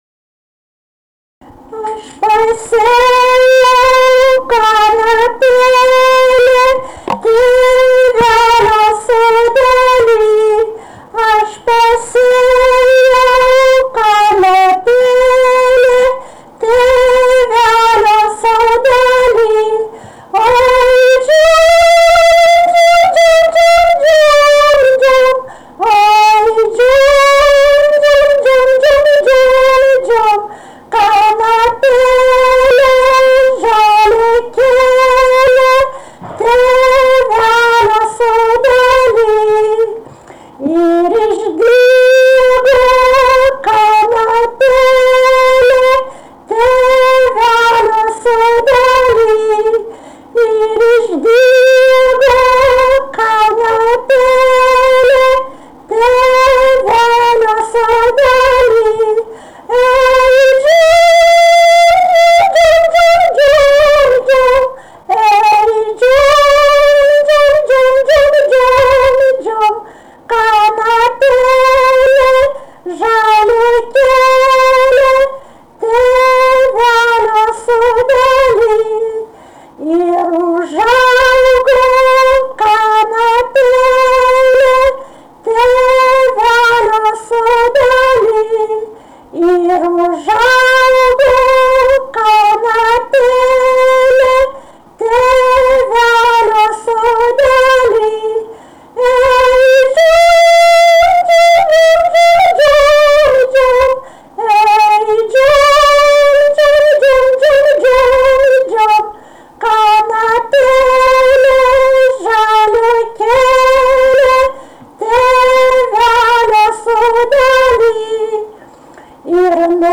daina, kalendorinių apeigų ir darbo
vokalinis